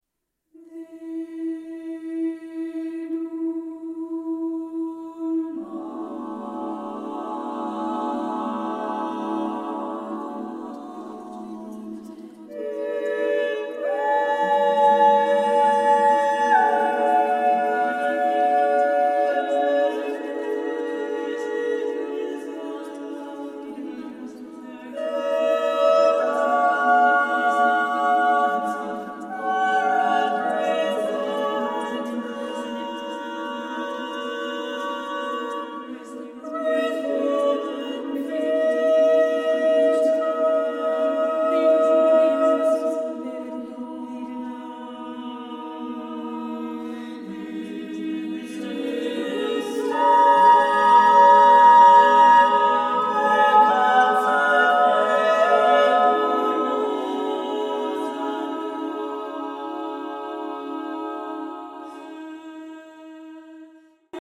Recorded August 8, 2021, Mechanics Hall, Worcester, MA